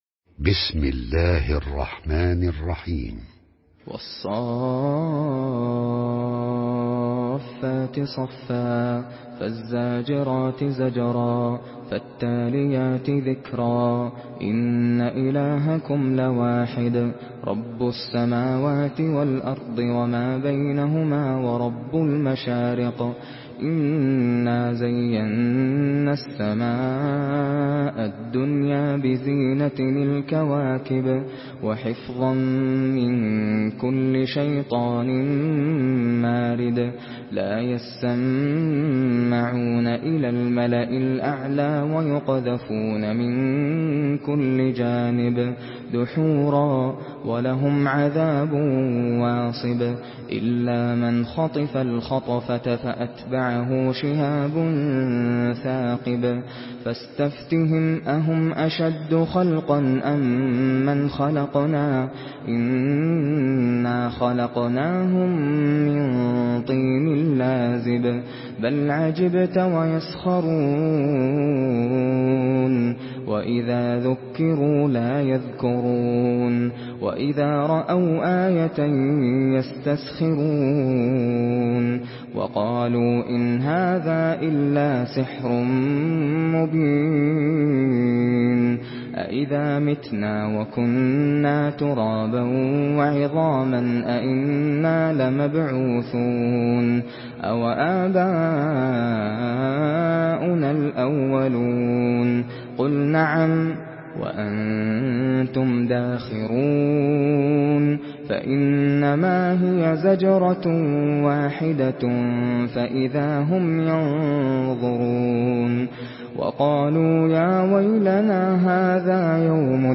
Surah আস-সাফ্‌ফাত MP3 in the Voice of Nasser Al Qatami in Hafs Narration
Surah আস-সাফ্‌ফাত MP3 by Nasser Al Qatami in Hafs An Asim narration.
Murattal Hafs An Asim